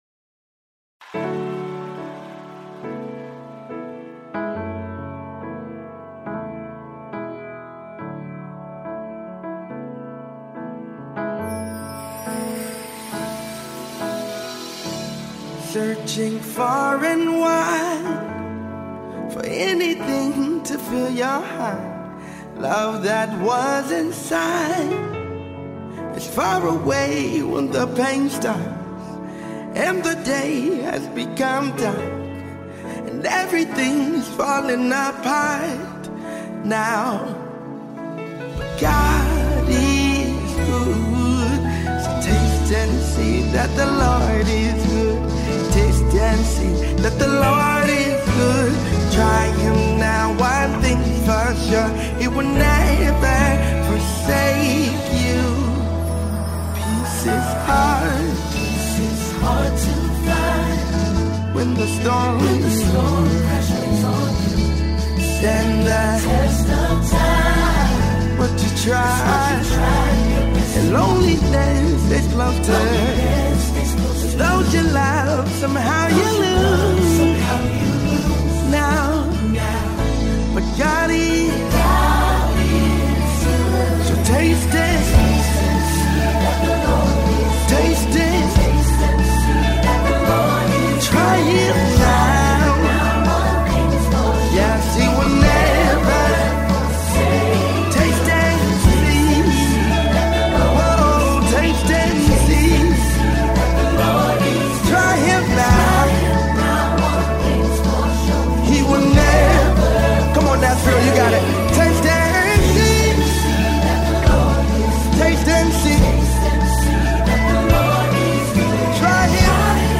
Live Music Video